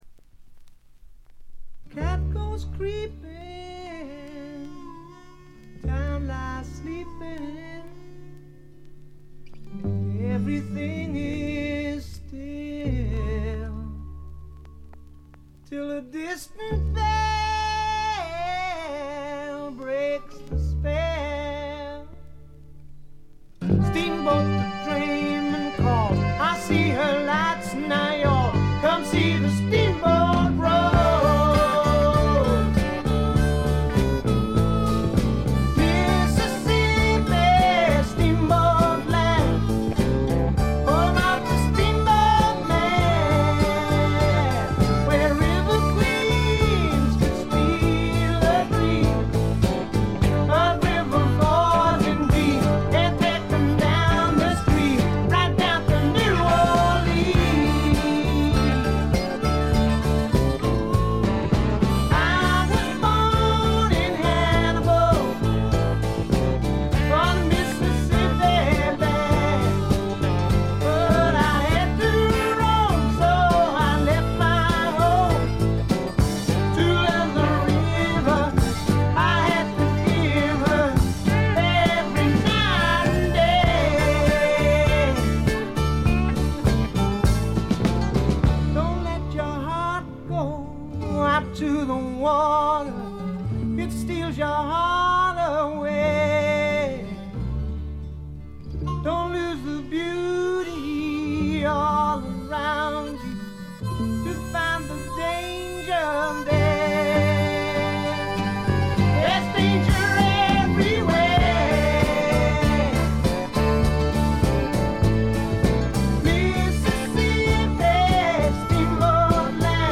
部分試聴ですが、軽微なチリプチ、バックグラウンドノイズ程度。
初期のマッギネス・フリントのようなスワンプ路線もありますが、それに加えて激渋ポップ感覚の漂うフォークロック作品です。
それにしてもこの人の引きずるように伸びのあるヴォーカルは素晴らしいでね。
試聴曲は現品からの取り込み音源です。